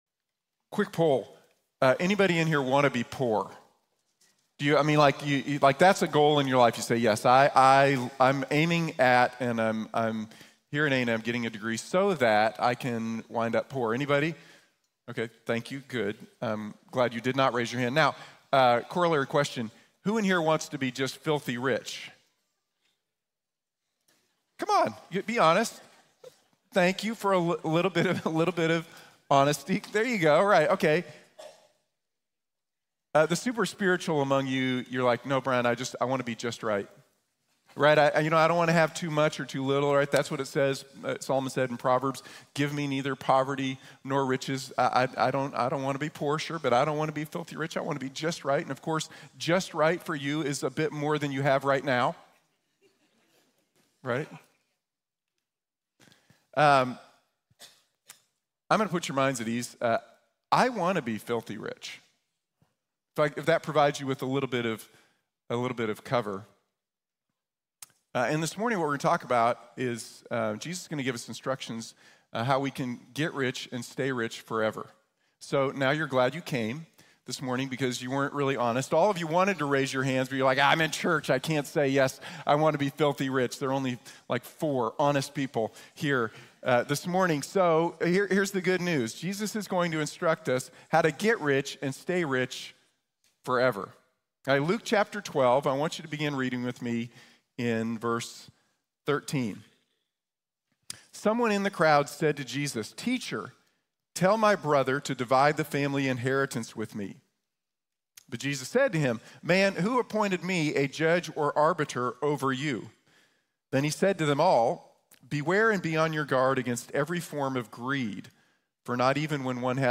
Invest in Eternity | Sermon | Grace Bible Church